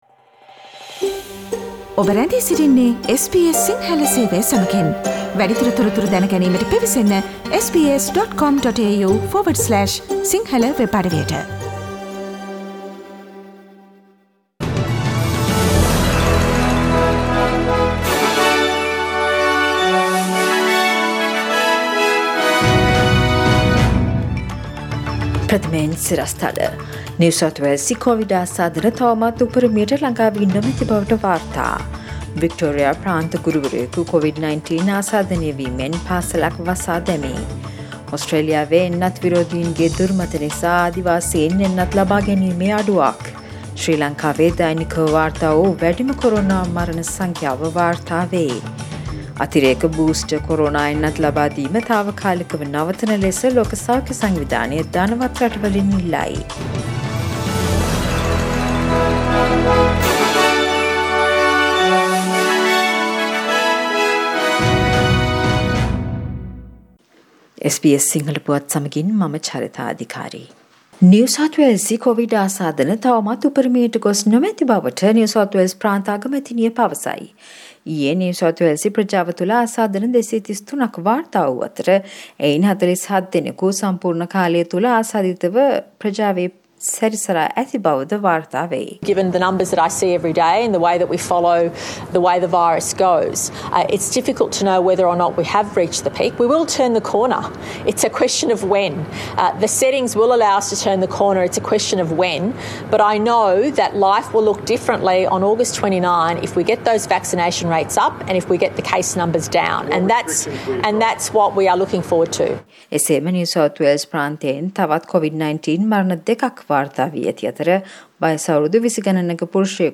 ඕස්ට්‍රේලියාවේ සහ ශ්‍රී ලංකාවේ අලුත්ම පුවත්, විදෙස් තොරතුරු සහ ක්‍රීඩා පුවත් රැගත් SBS සිංහල සේවයේ 2021 අගෝස්තු මස 05 වන බ්‍රහස්පතින්දා වැඩසටහනේ ප්‍රවෘත්ති ප්‍රකාශයට සවන්දෙන්න